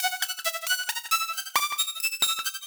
Index of /musicradar/shimmer-and-sparkle-samples/90bpm
SaS_Arp03_90-E.wav